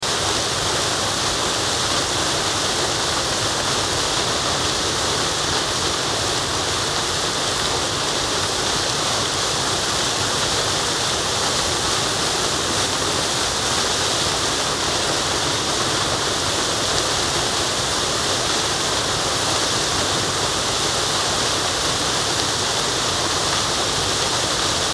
دانلود صدای آب 63 از ساعد نیوز با لینک مستقیم و کیفیت بالا
جلوه های صوتی